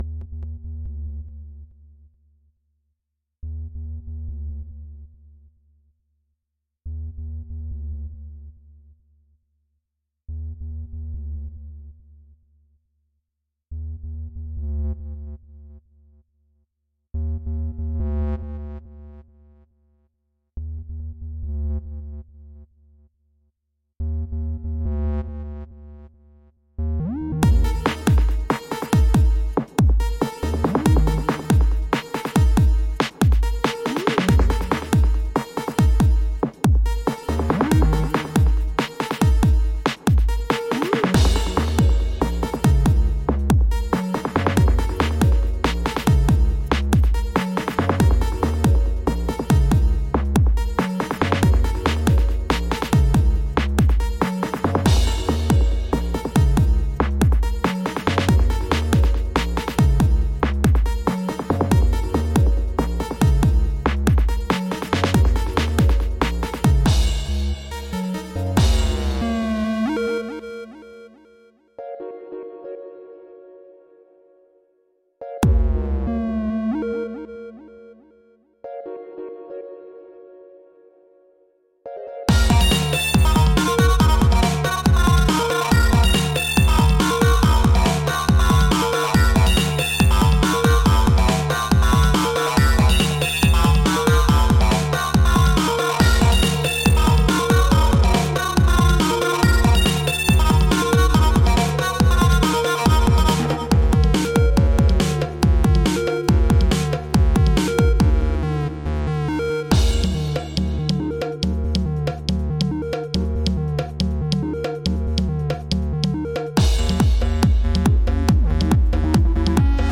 This track was made for a tracking Contest in Renoise in 2007.
An unembellished gruff indy rock vocal meets dry acid techno.
the arrangement suffers a bit from its simplicity but i really liked the 303-esque basslines, the overall soundquality and general impact on me as a listener.